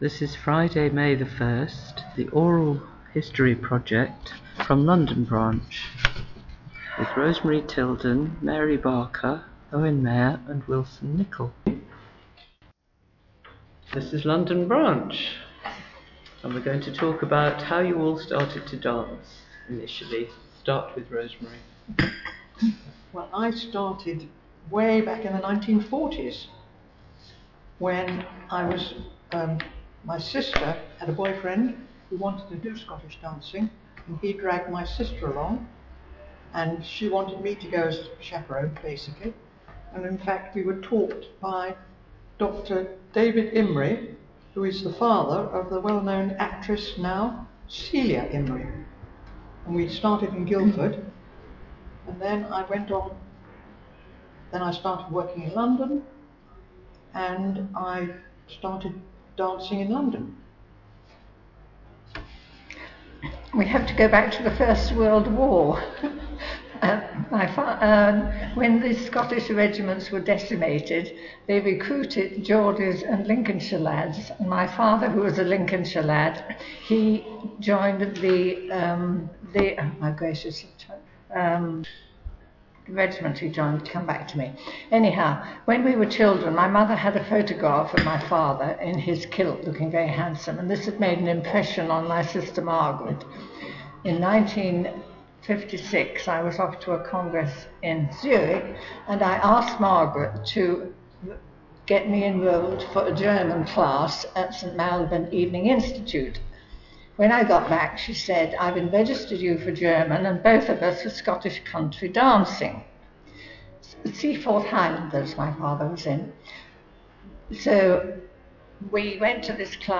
London Branch Oral Histories - RSCDS Archive
London_Branch_Oral_Histories.mp3